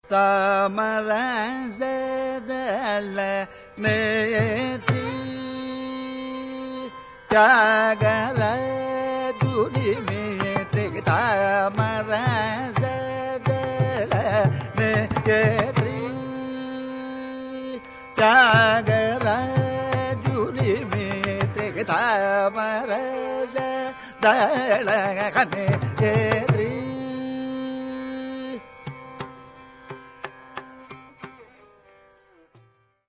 ") set in rāga Bhairavi and Aditāla.
violin
mridangam
Recorded in December 1967 in New York.
The pallavi and following improvisations are in Ādi tāla.
pallavi 6